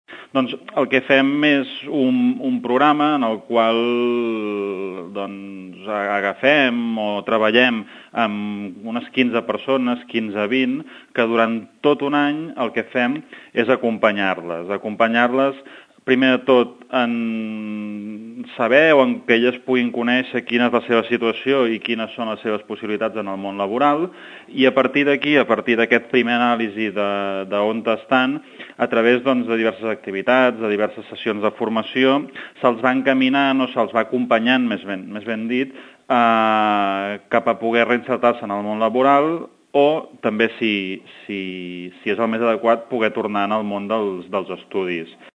L’objectiu principal del programa és la inserció laboral dels joves a través d’activitats diverses que, realitzades de forma individual o grupal, els permetran conèixer tècniques que millorin la realització dels currículums a nivell europeu, es faran cursos orientats a les necessitats dels joves, acompanyament i seguiment dels inscrits en la recerca de feina i contacte amb les empreses o activitats de potenciació de les capacitats individuals de cada jove que els permetin dissenyar el seu projecte de futur. Ho explica el regidor d’ocupació, Marc Unió.